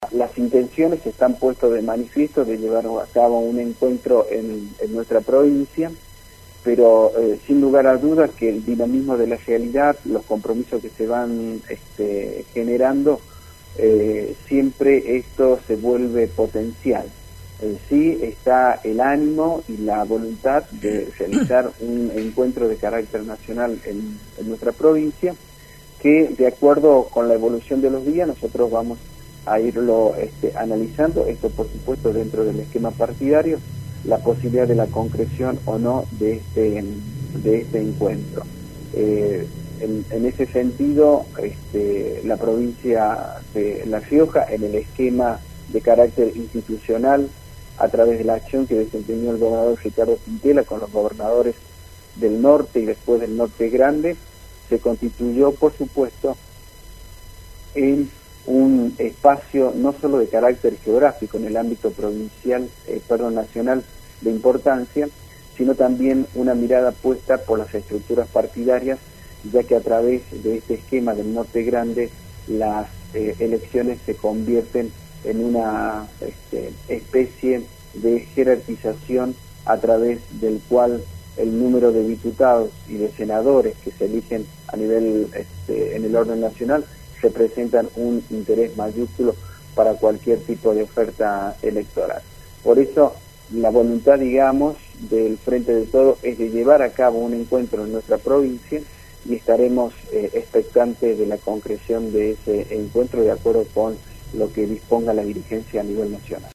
Pedro Goyochea, asesor general de Gobierno. Audio: Radio Nacional La Rioja